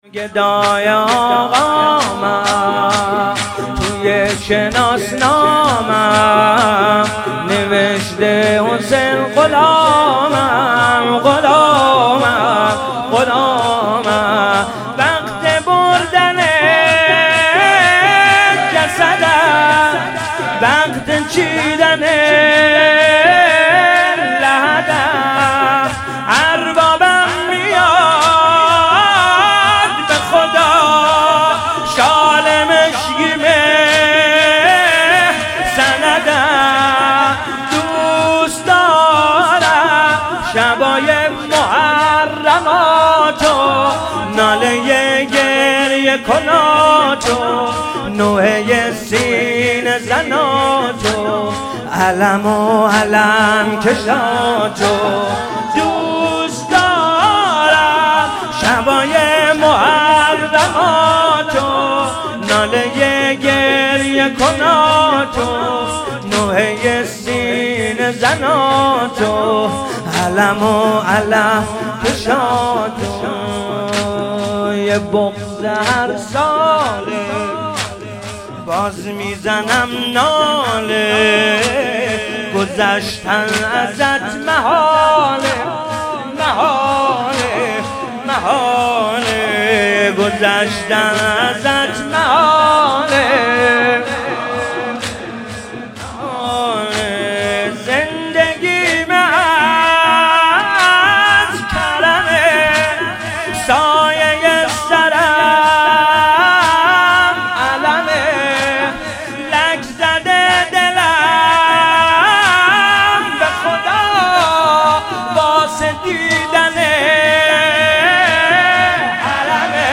مداحی شب جمعه کربلا